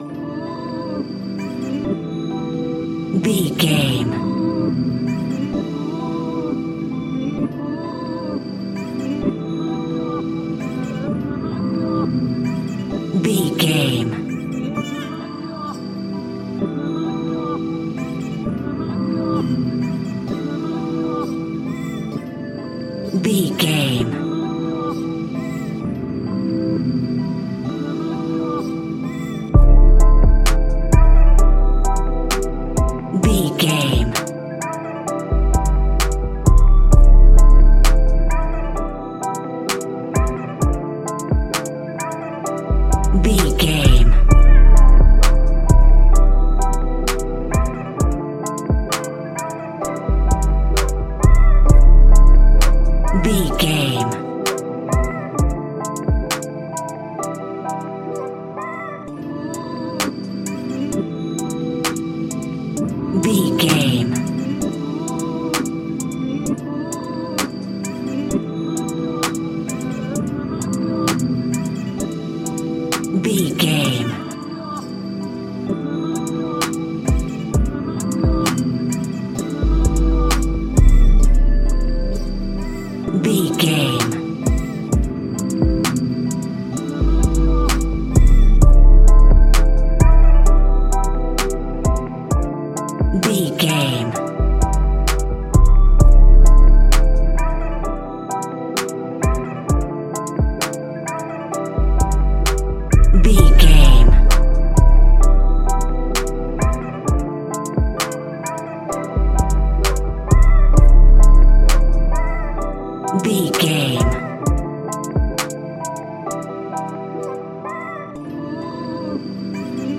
Ionian/Major
drums
smooth
calm
mellow